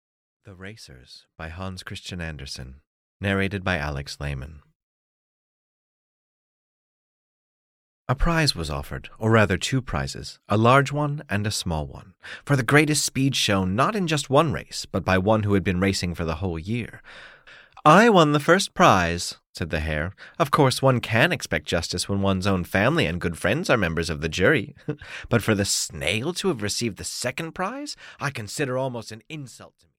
The Racers (EN) audiokniha
Ukázka z knihy